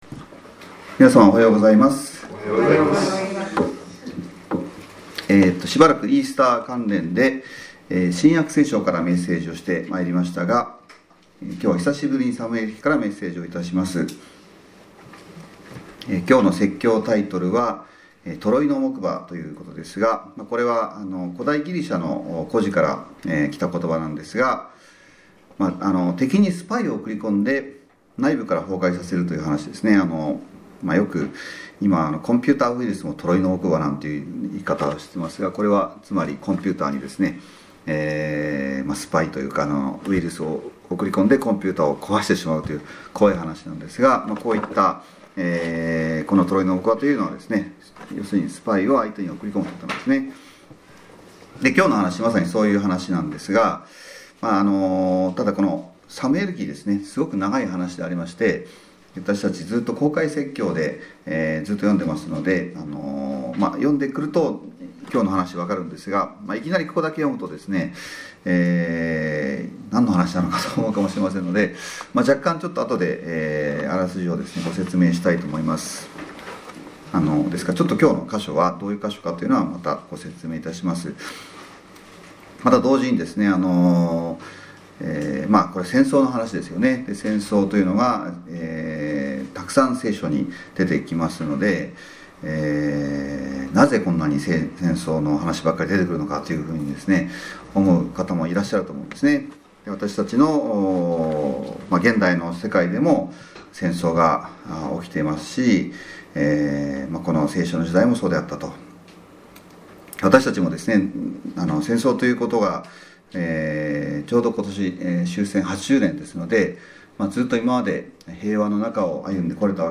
今日の説教タイトルは「トロイの木馬」ですが、これは古代ギリシアの故事から取られたことばで、敵にスパイを送り込んで内部から崩壊させるという話です。そして本日の聖書箇所は、まさにそのような内容になっています。